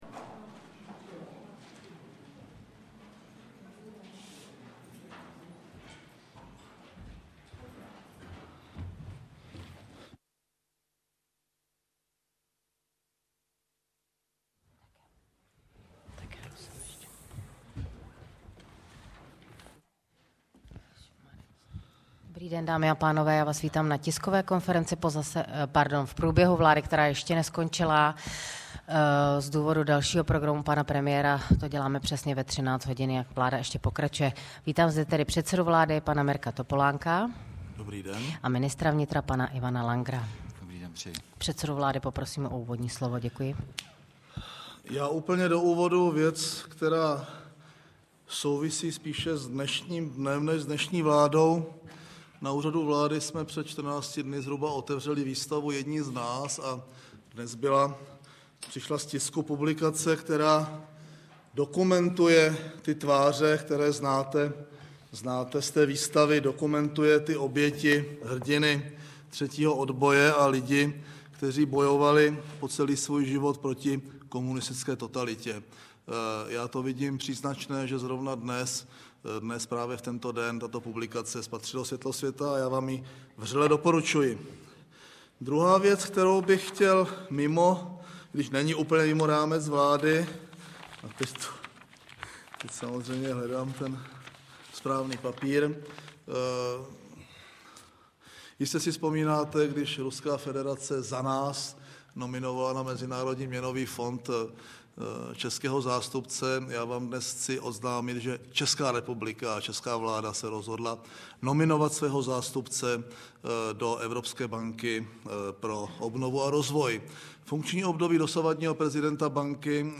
Tisková konference po jednání vlády ČR 25.2.2008